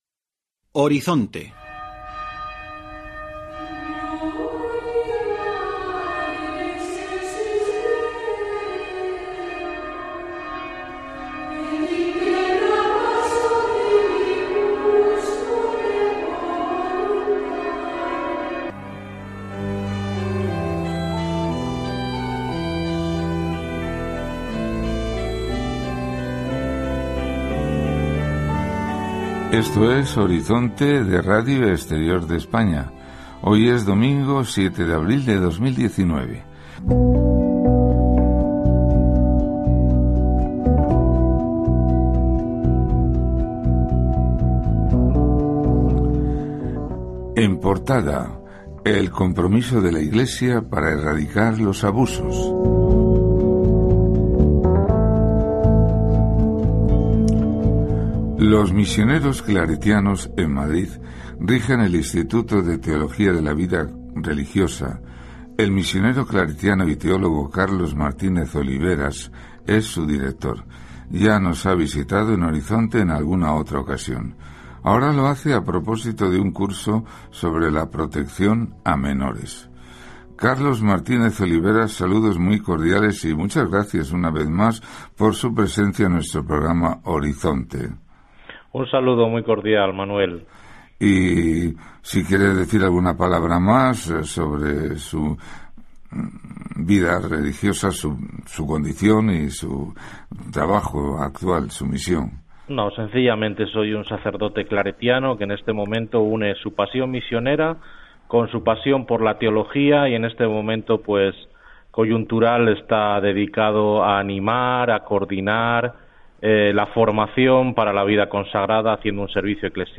Horizonte Entrevista proteccion de menores.mp3